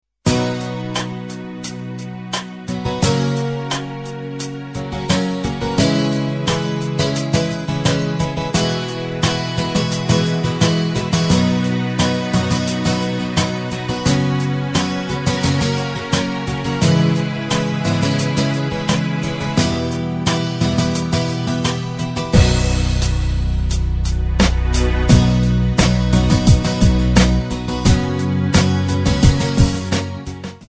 Ab
Backing track Karaoke
Pop, 2000s